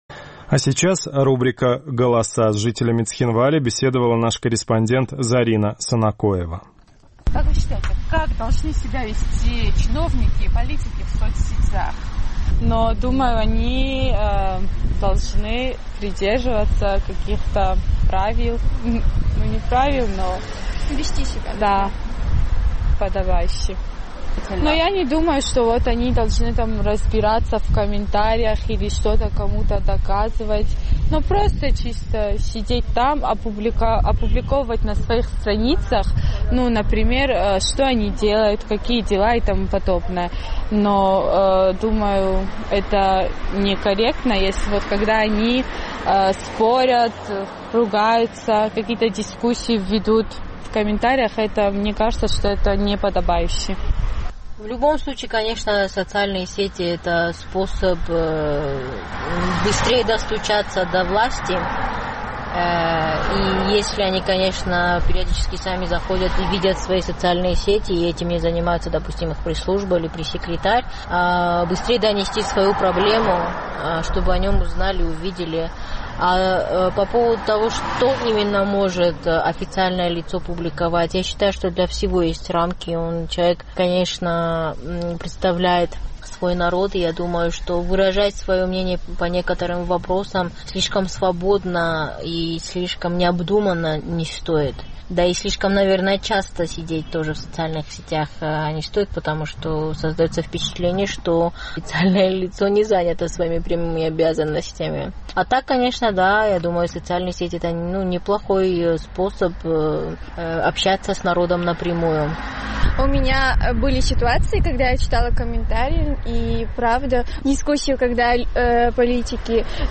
Наш цхинвальский корреспондент поинтересовалась у местных жителей, как чиновники должны вести себя в соцсетях.